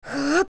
• The files of Ocarina of Time contain one unused audio clip of the Hero of Time's grunts based on both his
OOT_YoungLink_Grunt.wav